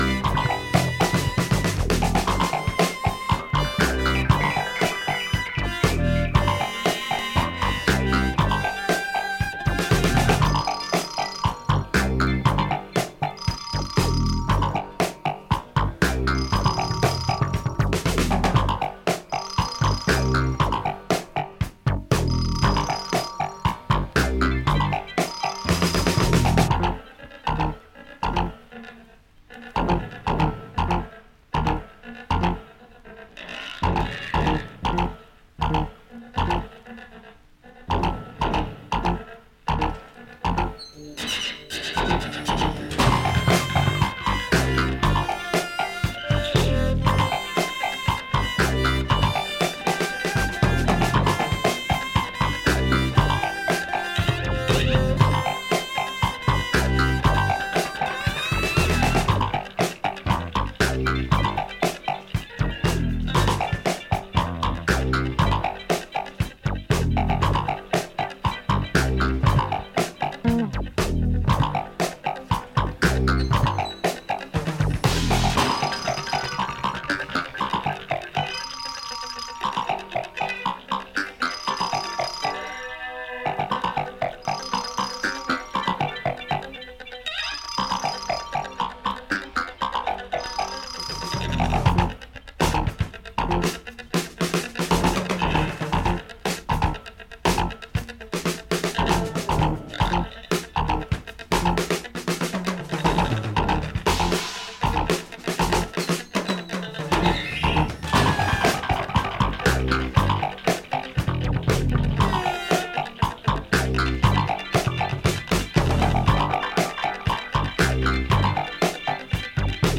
newly remastered from the original tapes